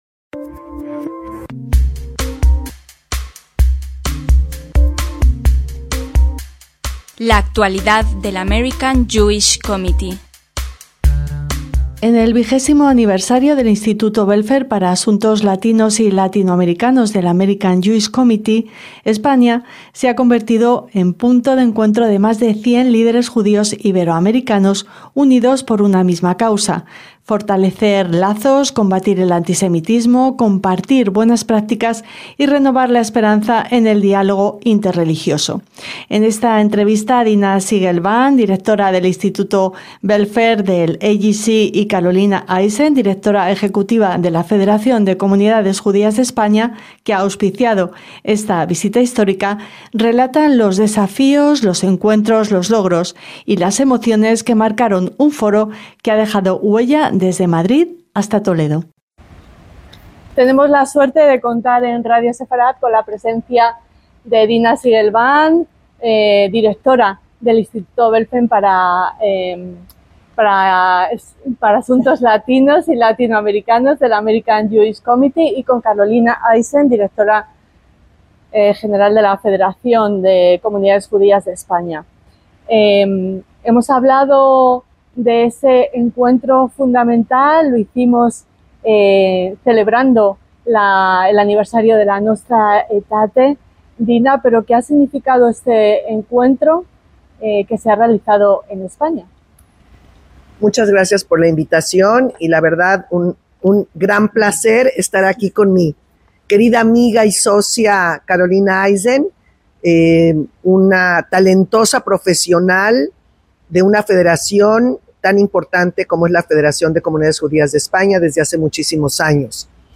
LA ACTUALIDAD DEL AMERICAN JEWISH COMMITTEE - En el vigésimo aniversario del Instituto Belfer para Asuntos Latinos y Latinoamericanos del American Jewish Committee AJC, España se ha convertido en punto de encuentro de más de 100 líderes judíos iberoamericanos unidos por una misma causa: fortalecer lazos, combatir el antisemitismo, compartir buenas prácticas y renovar la esperanza en el diálogo interreligioso. En esta entrevista